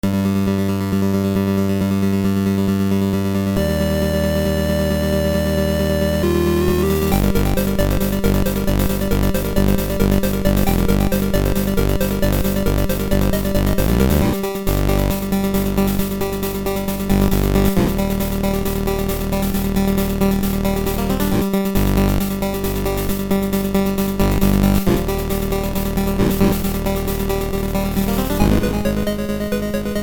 Boss theme